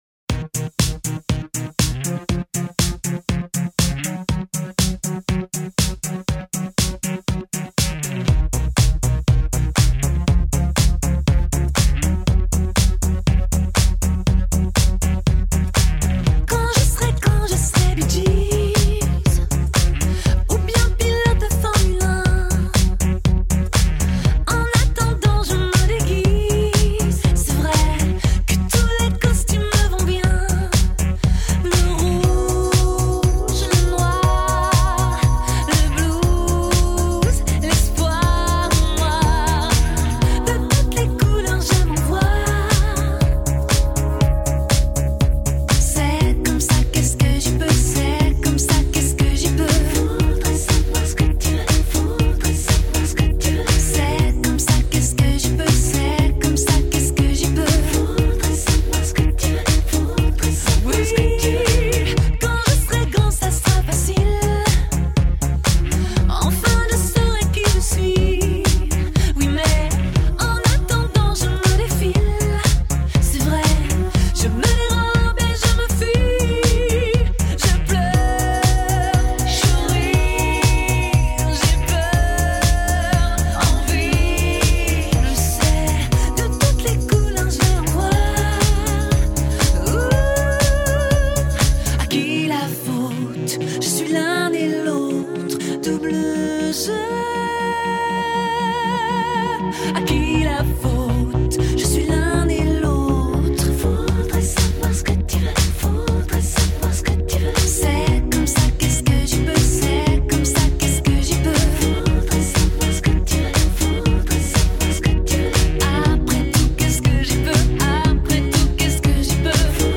so Kylie Minogue, so "Dancing queen"